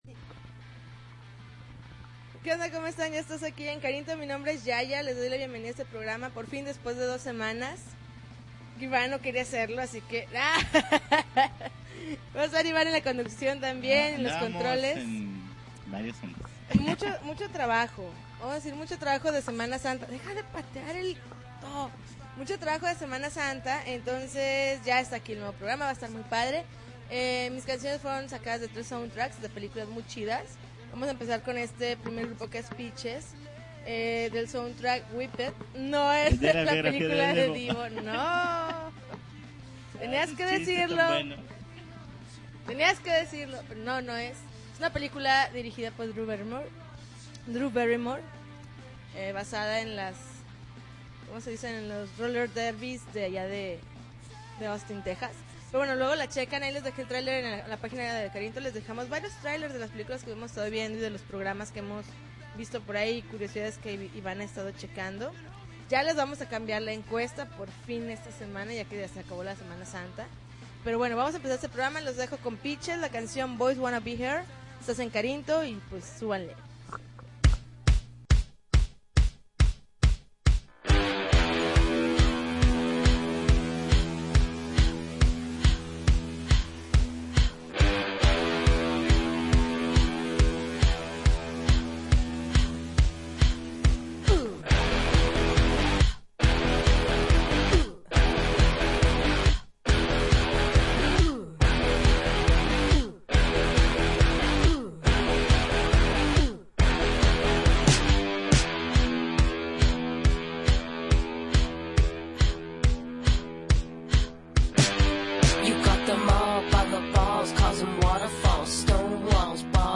April 11, 2010Podcast, Punk Rock Alternativo